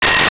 Sci Fi Beep 08
Sci Fi Beep 08.wav